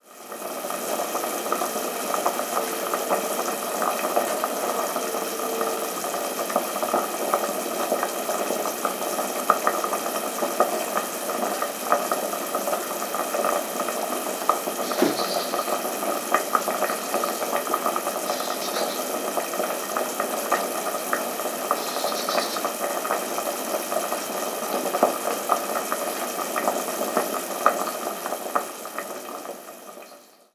Cocer en un puchero
Sonidos: Agua
Sonidos: Hogar